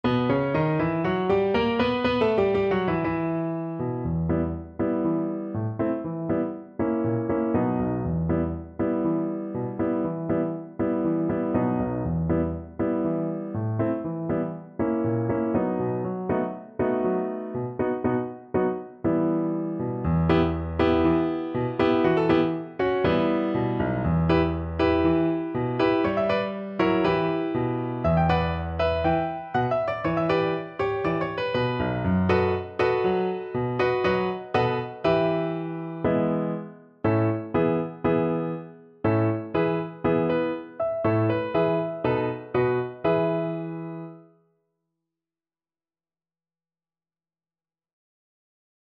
Varshaver Freylekhs (Klezmer)
Play (or use space bar on your keyboard) Pause Music Playalong - Piano Accompaniment Playalong Band Accompaniment not yet available transpose reset tempo print settings full screen
B minor (Sounding Pitch) (View more B minor Music for Violin )
Allegro (View more music marked Allegro)
2/4 (View more 2/4 Music)